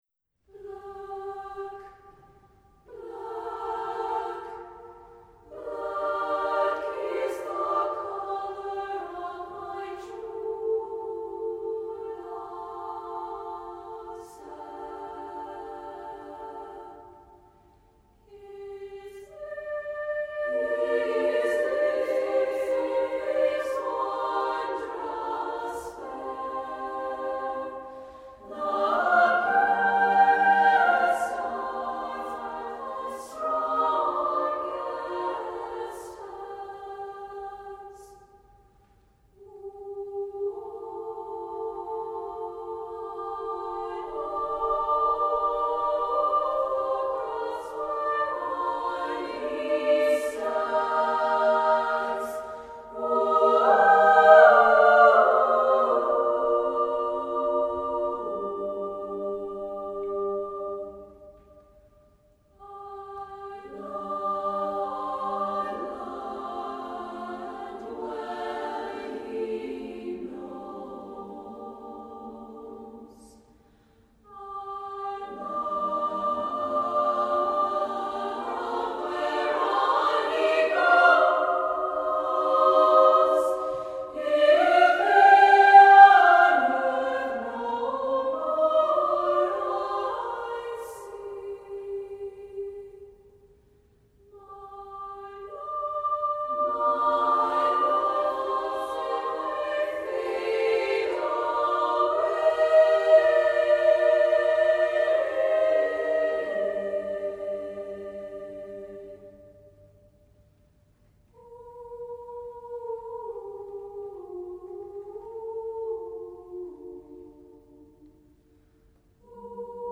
Voicing: SSAA a cappella